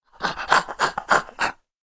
DayZ-Epoch/SQF/dayz_sfx/zombie/idle_35.ogg at e7dbc5dd4c398cab3ab37f07585d445d71a590d1